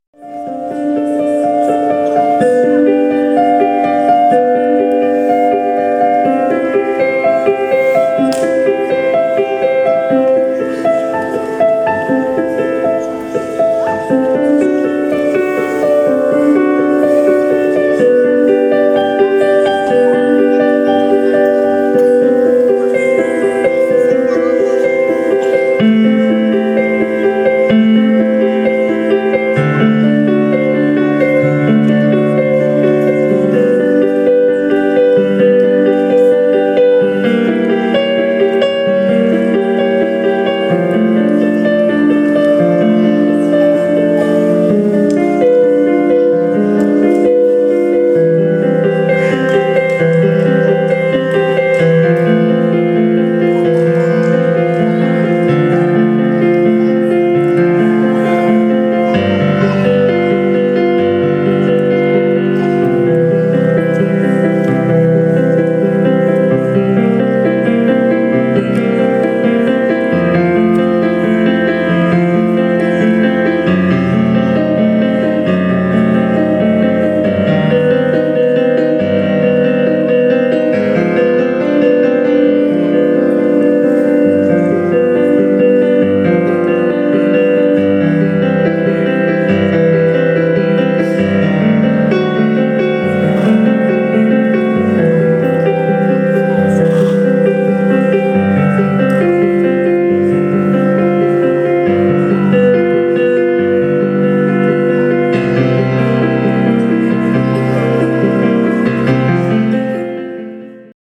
piano
Concert à l'école.